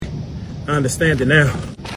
Instant meme sound effect perfect for videos, streams, and sharing with friends.